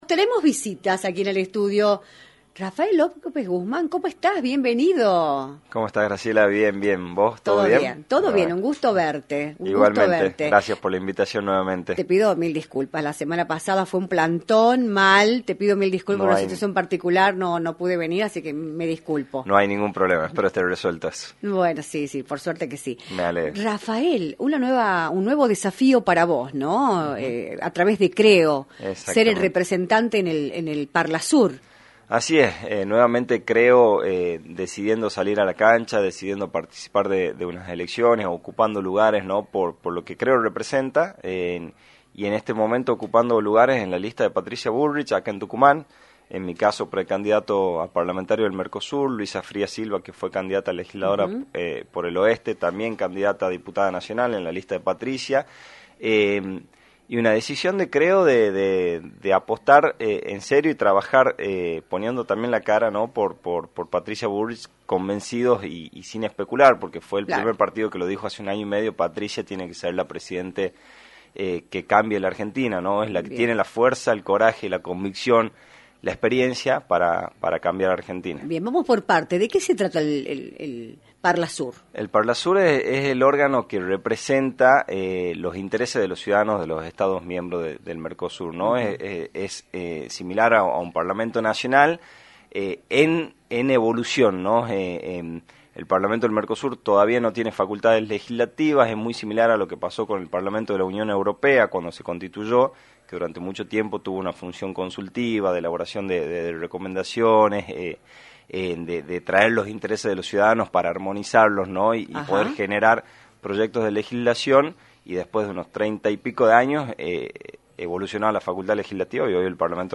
visitó los estudios de “Libertad de Expresión”, por la 106.9 para analizar el escenario político nacional
entrevista